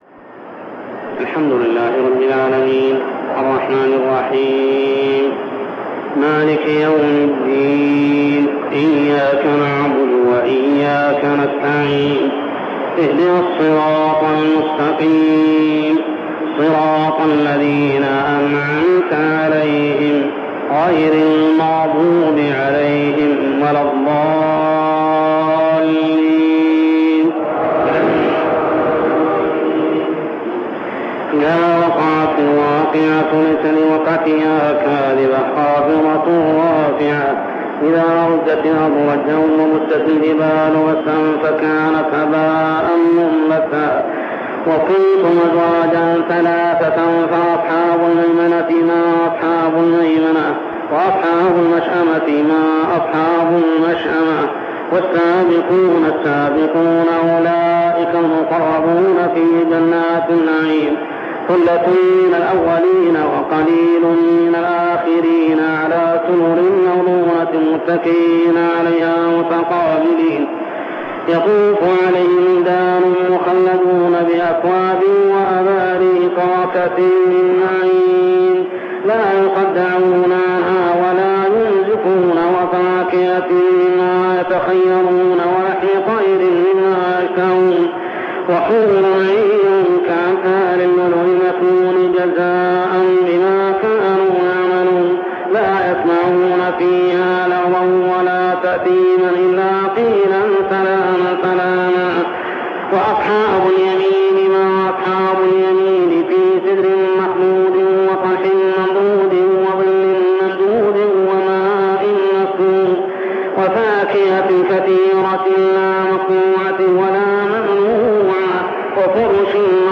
صلاة التراويح عام 1402هـ من سورة الواقعة كاملة حتى سورة الصف 1-11 | Tarawih Prayer from Surah Al-Waqi'ah to As-Saff > تراويح الحرم المكي عام 1402 🕋 > التراويح - تلاوات الحرمين